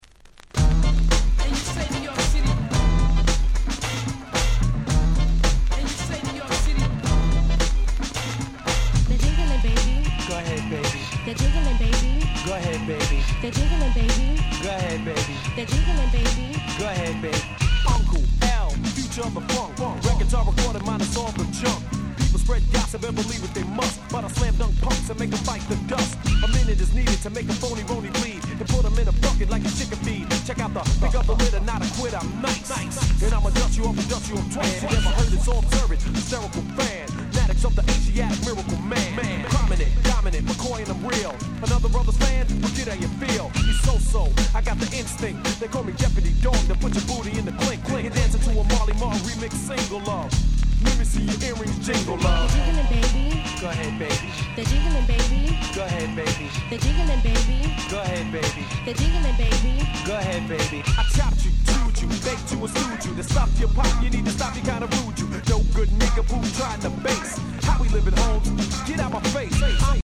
90's 80's Boom Bap ブーンバップ Old School オールドスクール